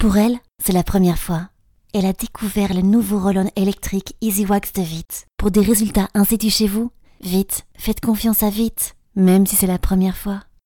Female
Smooth, warm, dynamic and young voice.
Radio Commercials